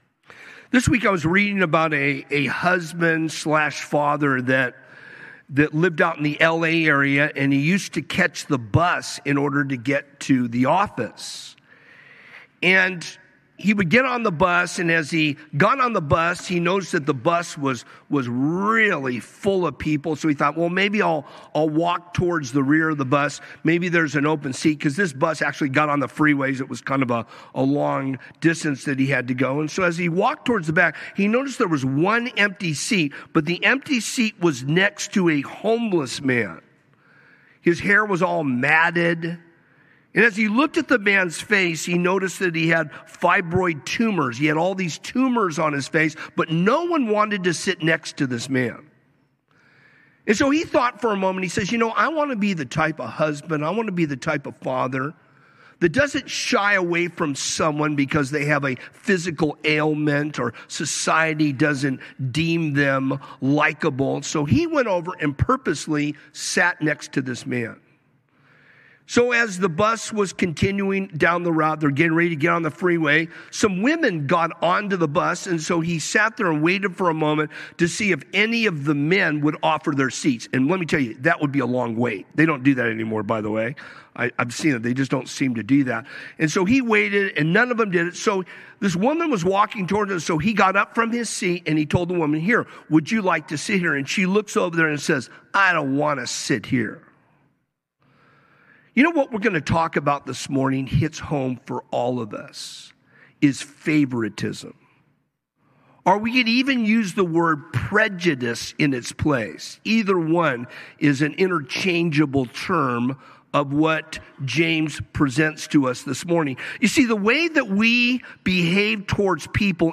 A message from the series "Sunday Morning - 10:30."